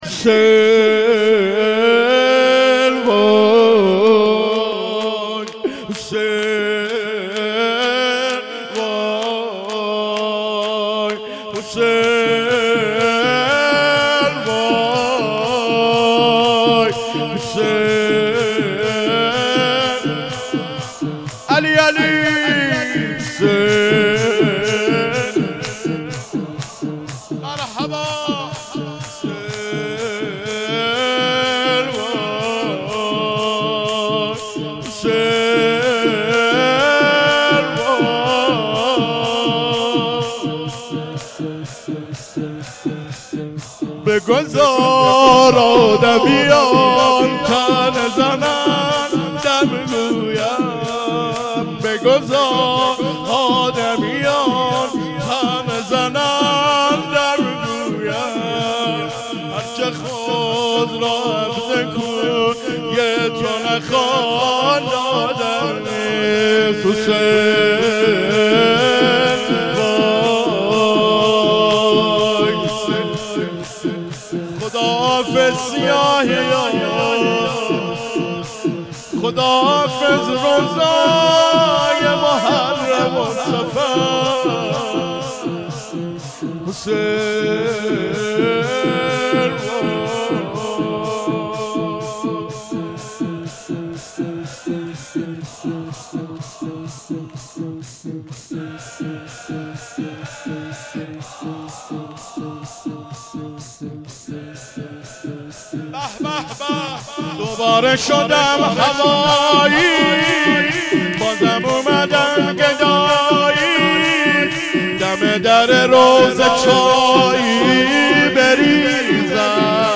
شور مراسم عزاداری صفر